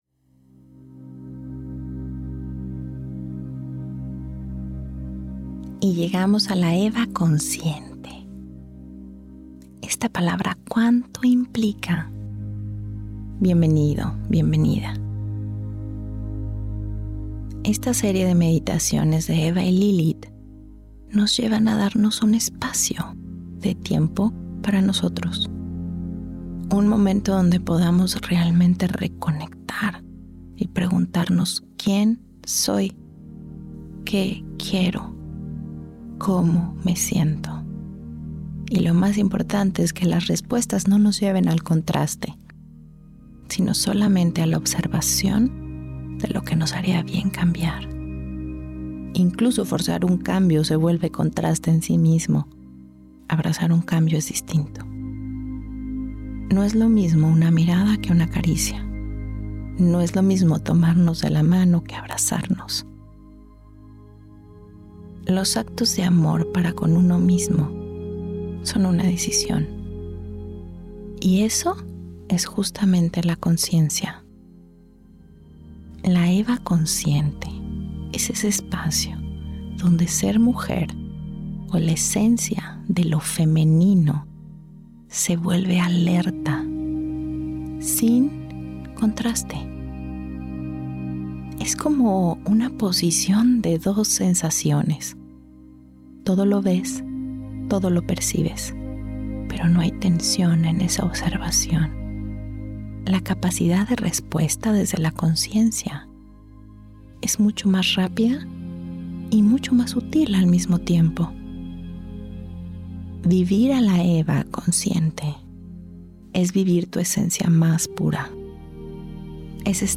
Meditaciones Eva Consciente Esta Eva nos conecta con nuestra intuición ya desarrollada después de haber atravesado arduos retos.